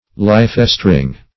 Meaning of lifestring. lifestring synonyms, pronunciation, spelling and more from Free Dictionary.